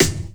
Snares
SNARE_KONZ.wav